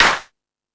Hip House(39).wav